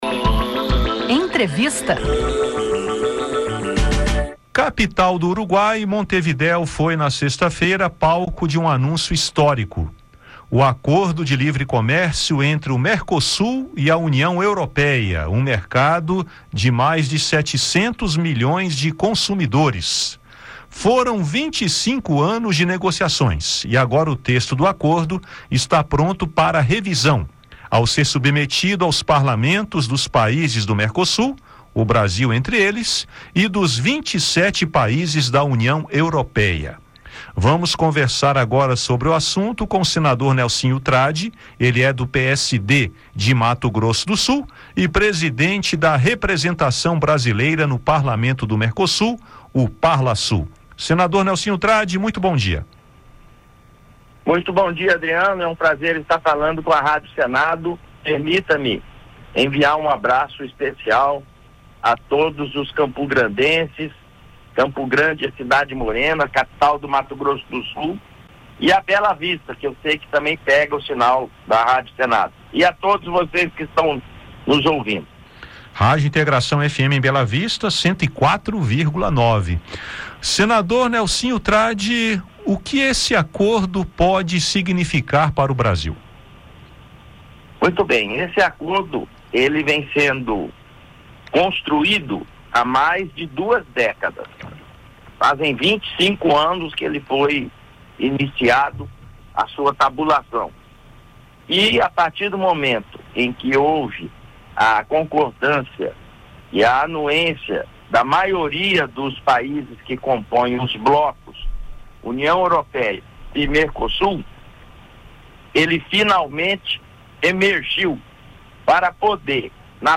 Nelsinho Trad fala ao Conexão Senado sobre o acordo Mercosul-UE
O senador Nelsinho Trad (PSD-MS), que preside a Representação Brasileira no Parlamento do Mercosul, fala sobre o acordo comercial entre o bloco sul-americano e a União Europeia. Trad explica as possibilidades econômicas para o Brasil com a efetivação do acordo, especialmente para o agronegócio. Também esclarece os motivos da rejeição ao acordo por países como a França e a Polônia.